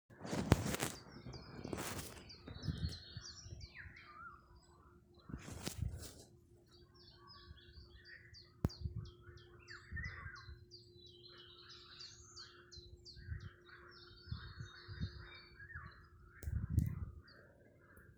Mazais svilpis, Carpodacus erythrinus
StatussDzied ligzdošanai piemērotā biotopā (D)
PiezīmesKārklu krūmājā, blakus pārplūdis dīķis, arī krūmājs ir ūdenī. Fonā dzied čunčiņš